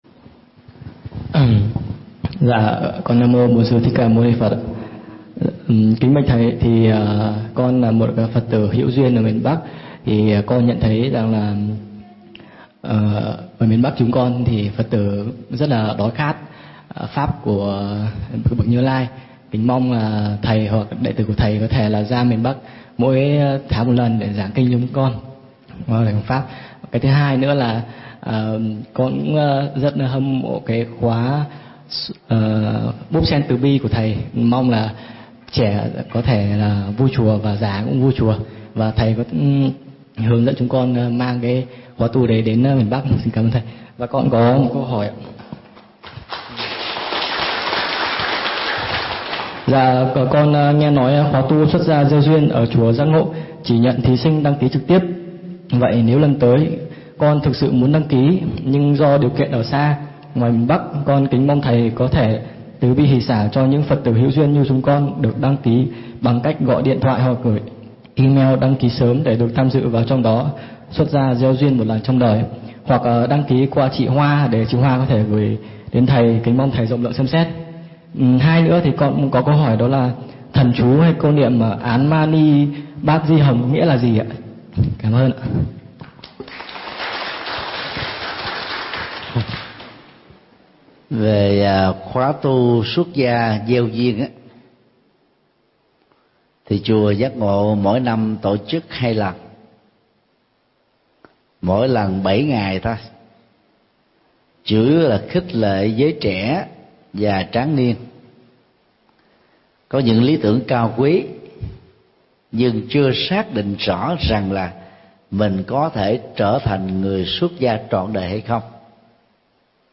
Mp3 Vấn Đáp Hiểu cho đúng về giá trị tụng thần theo tinh thần đạo Phật